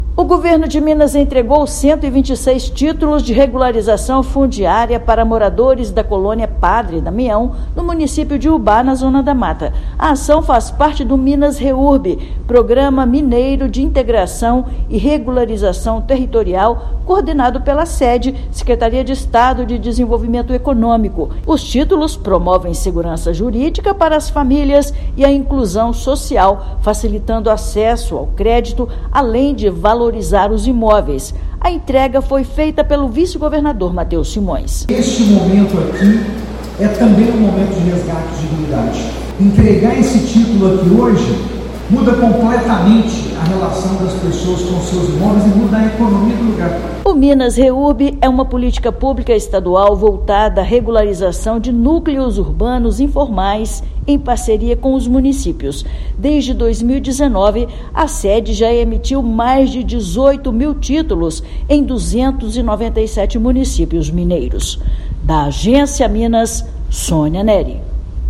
Município da Zona da Mata já soma quase 600 imóveis regularizados por meio do programa Minas Reurb. Ouça matéria de rádio.